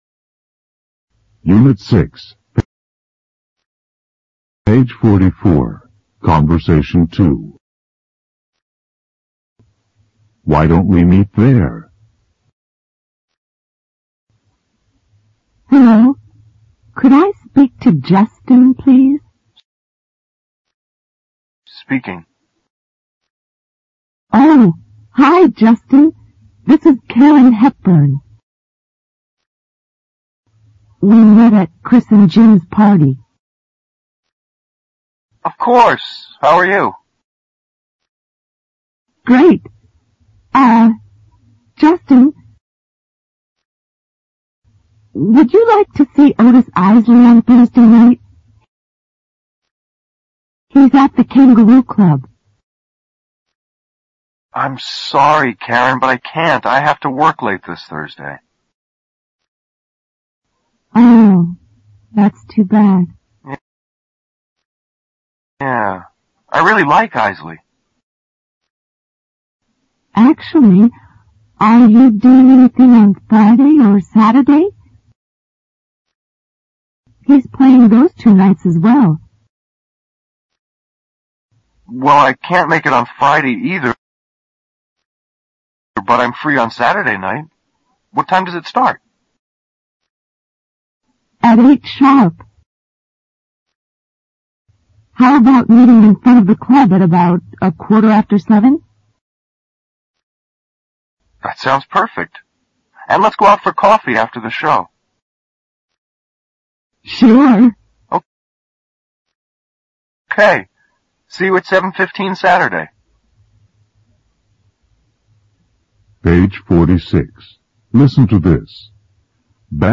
简单英语口语对话 unit6_conbersation2_new(mp3+lrc字幕)